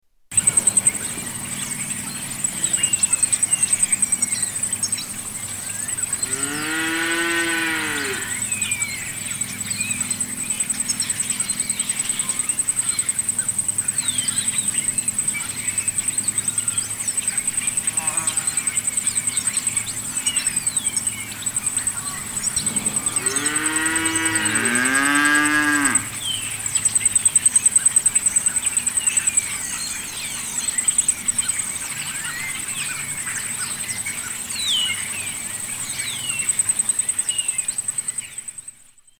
Summer at Huon Valley